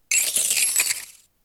Fichier:Cri 0707 XY.ogg